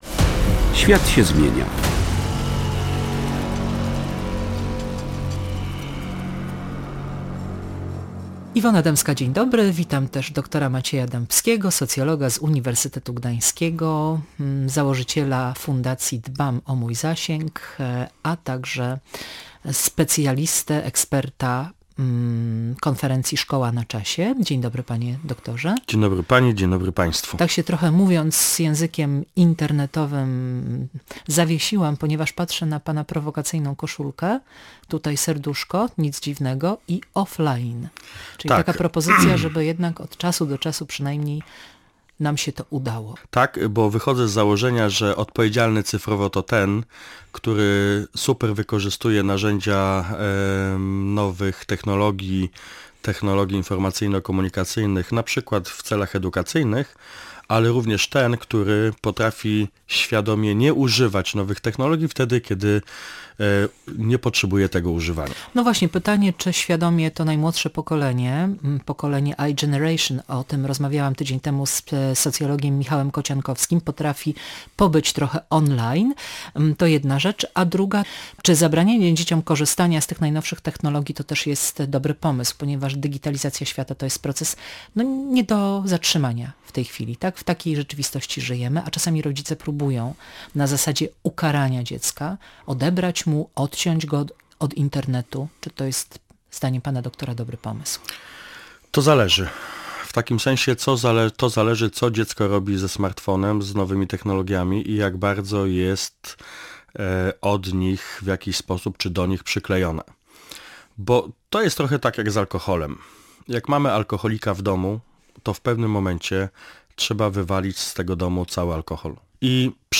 Rozmowa z socjologiem